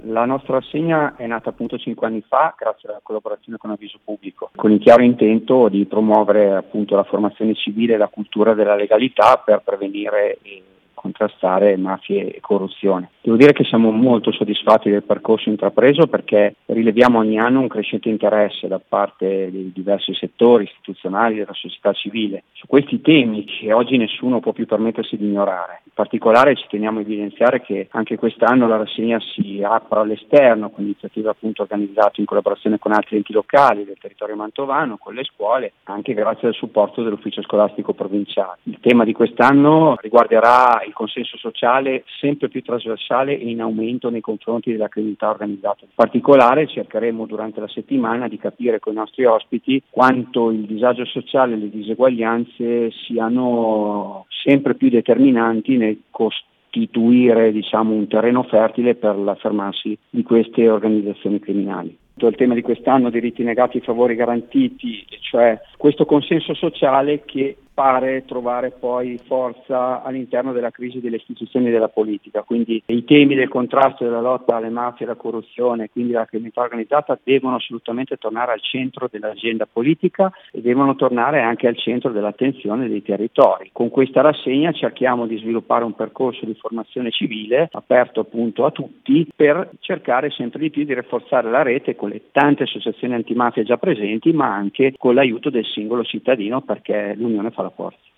Il Sindaco di Gazoldo degli Ippoliti, Nicola Leoni, ci racconta quali sono gli obiettivi principali di questa manifestazione e approfondisce il tema del focus di questa edizione: